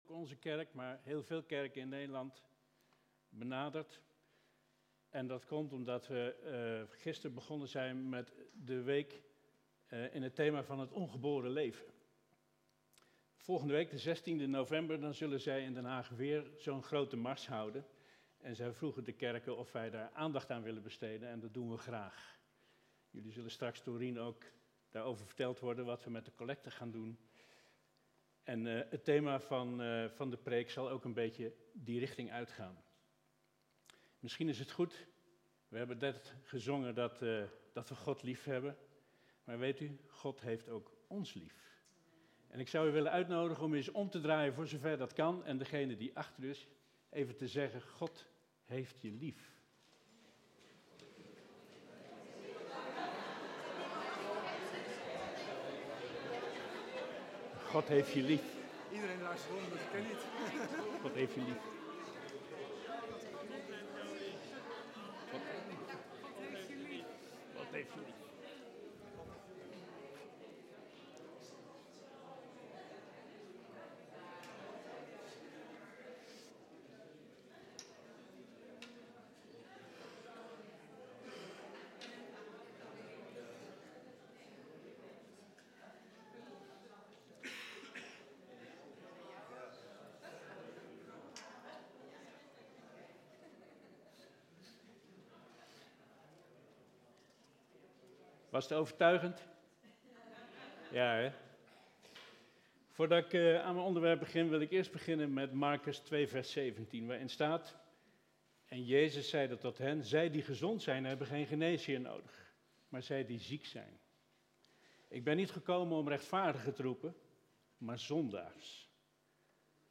tussen ca. 5min30sec en 6min14sec is er even geen geluid